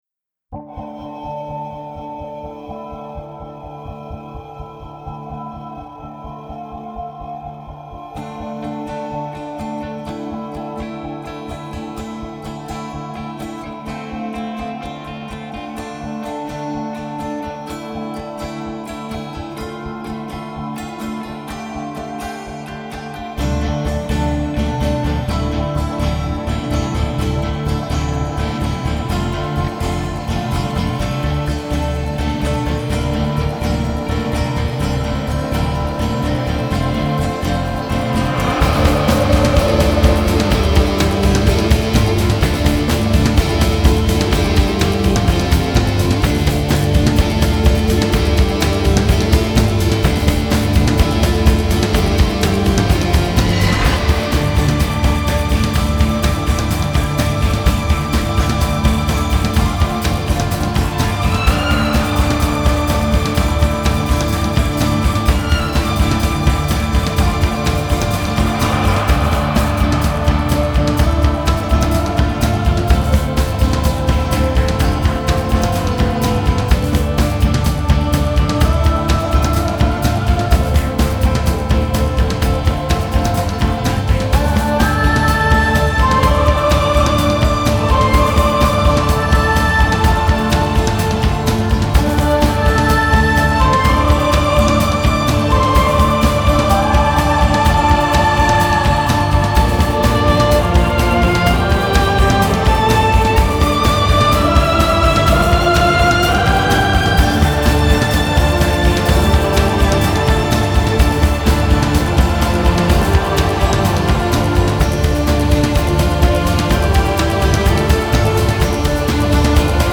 موسیقی بی کلام
موسیقی حماسی
epic music